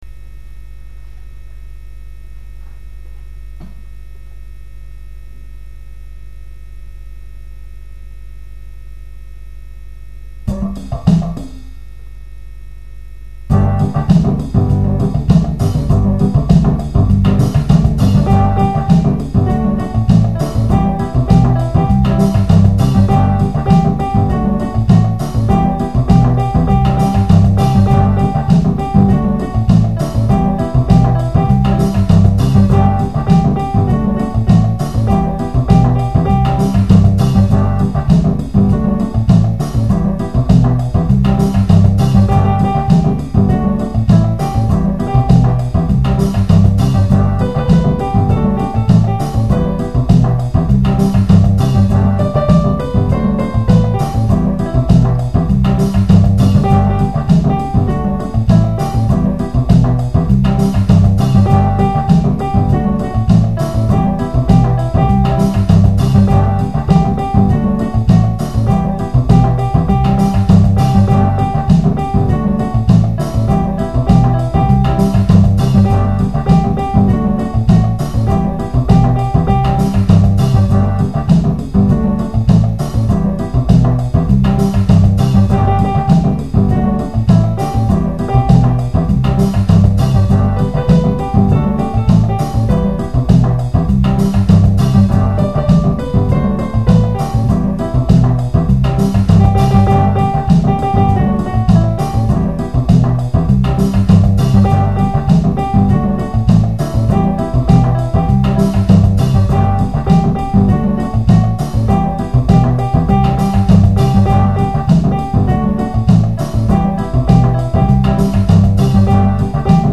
Apren a tocar la flauta